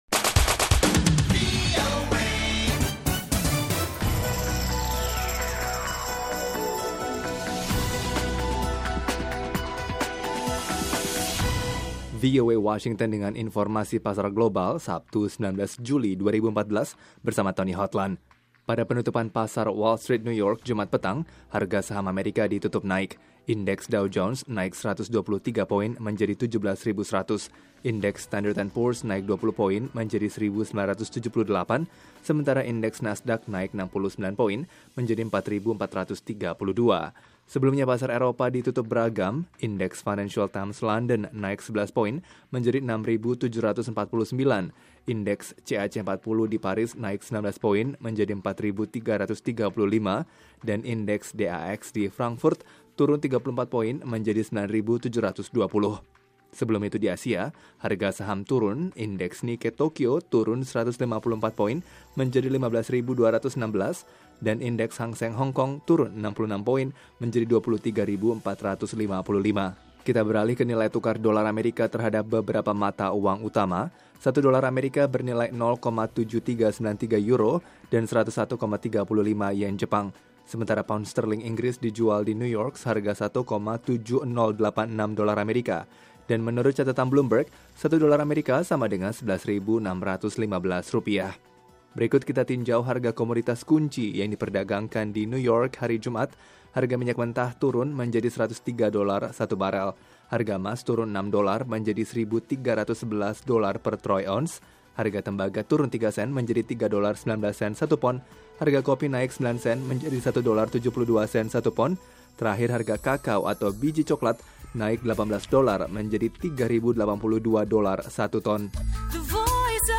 mengantarkan berita terkini seputar Info Pasar Keuangan dan Bursa Global hari ini, Sabtu 19 Juli 2014.